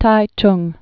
(tīchng, -jng)